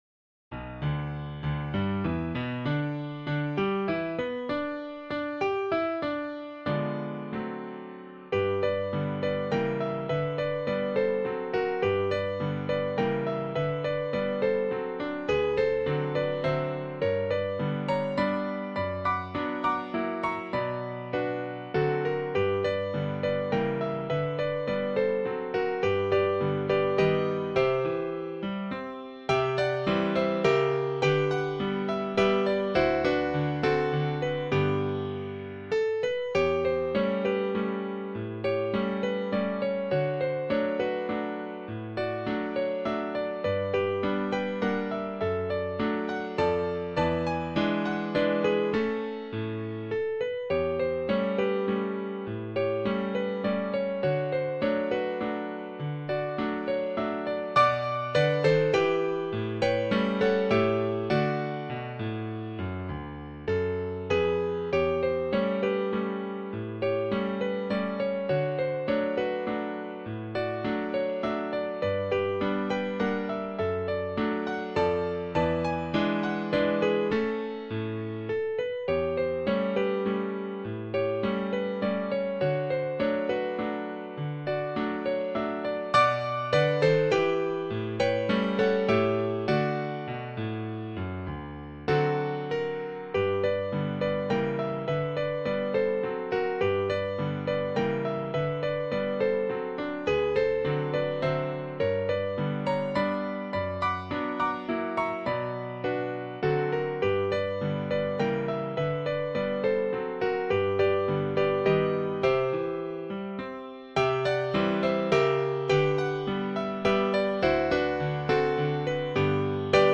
for piano as an mp3 file or play it below